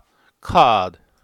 [kɑːd]